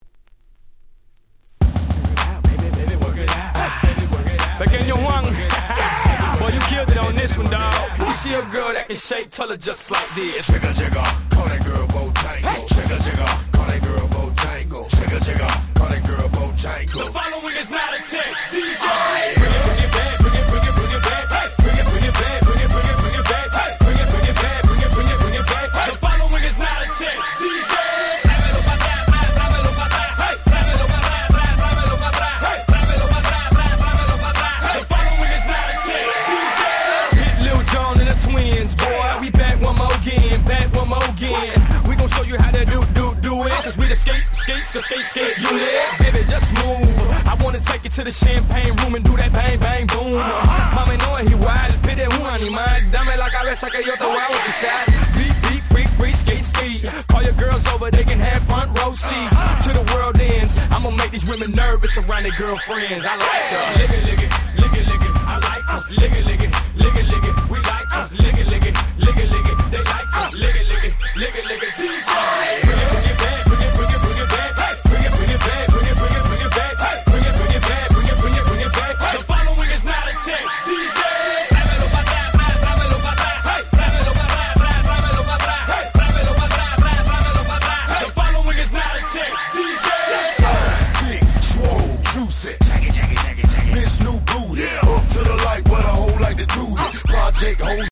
06' Smash Hit Southern Hip Hop !!
サウス South 00's アゲアゲ